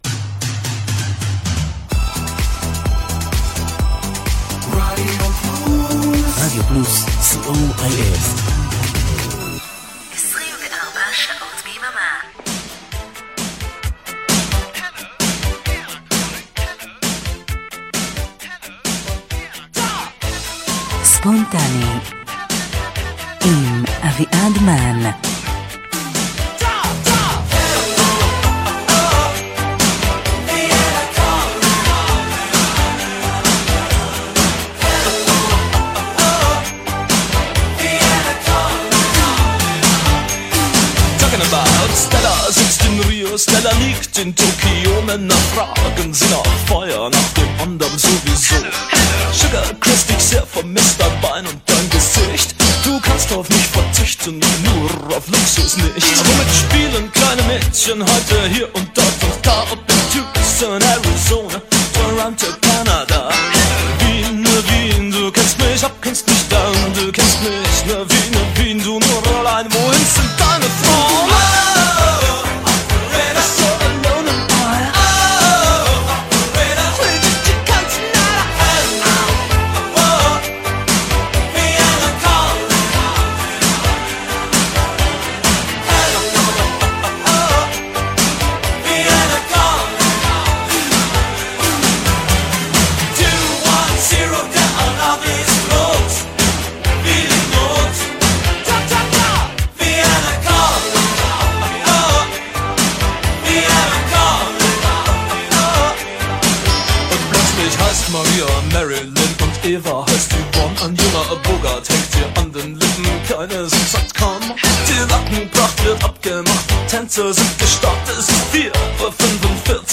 שעה קלילה לקראת שבת… מתחילה עם הרבה גרוב, ממשיכה ברגוע, ומסתיימת בסיפור מקסים – כי פטור בלא כלום אי אפשר.